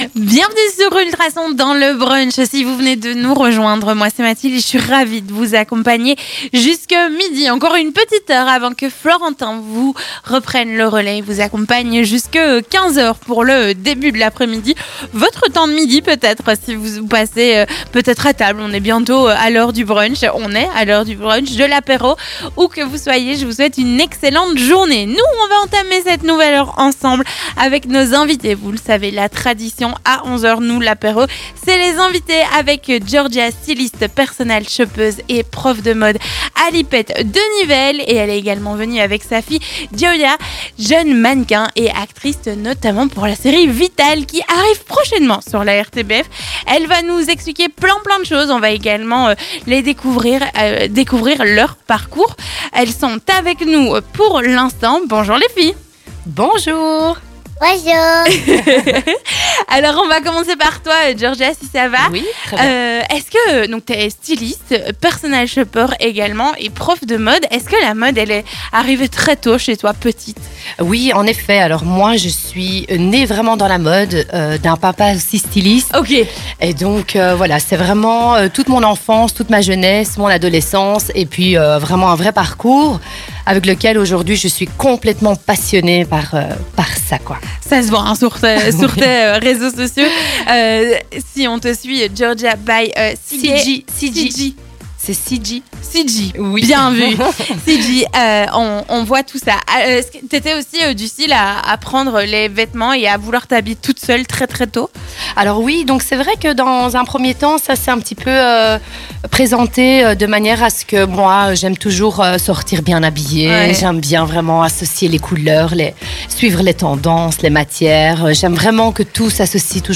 Elles évoquent ensemble leur parcour autour de la mode et des caméras, leur agendas familiale autour d'une carrière solo ou commune chacune, au fil de l'interview !